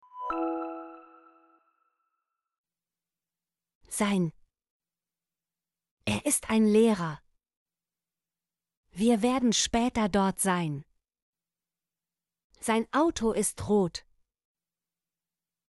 sein - Example Sentences & Pronunciation, German Frequency List